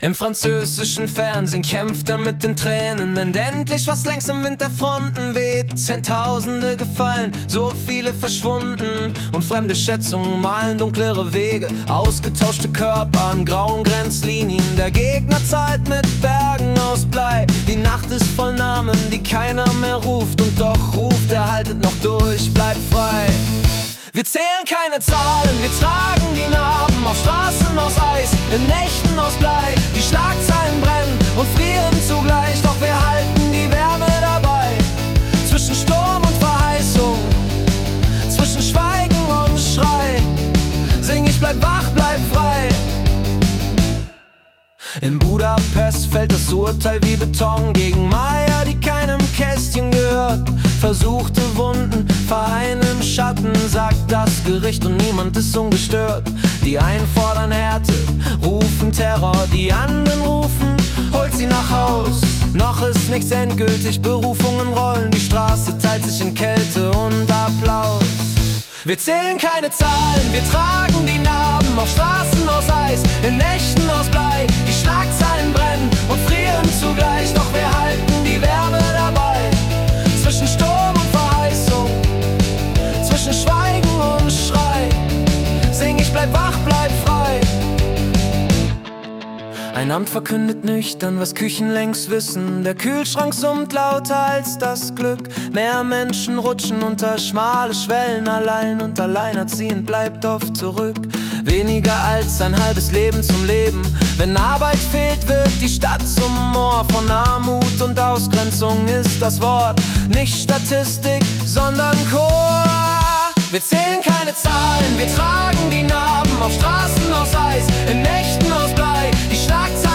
Jede Folge verwandelt die letzten 24 Stunden weltweiter Ereignisse in eine originale Singer-Songwriter-Komposition.